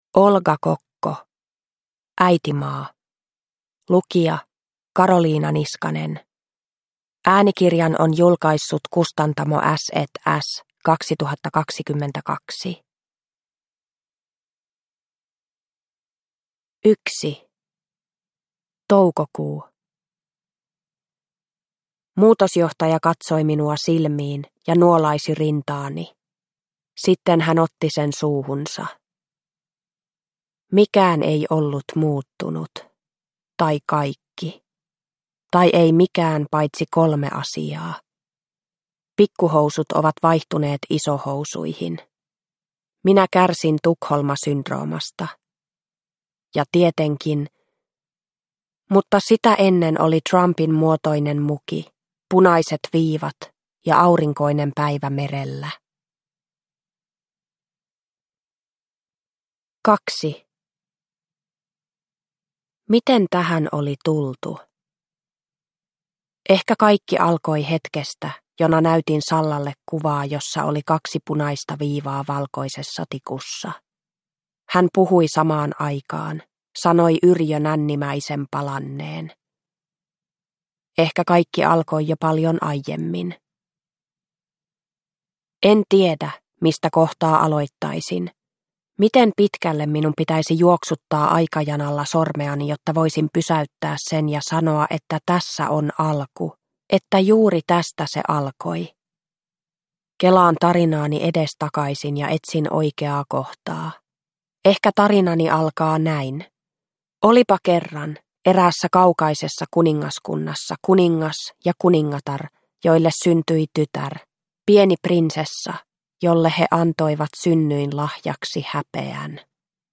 Äitimaa – Ljudbok – Laddas ner